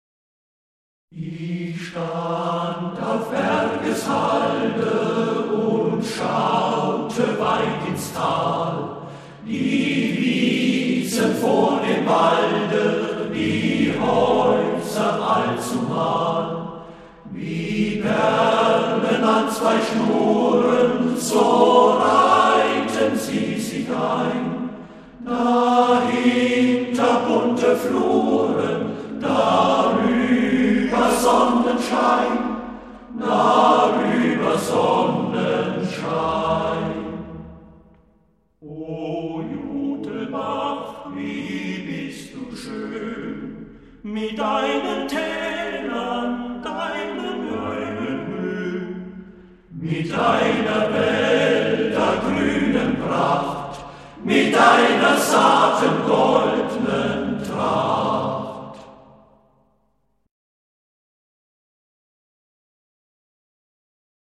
A-capella-Chorgesang
1996 / Volkslieder und weltliche Chormusik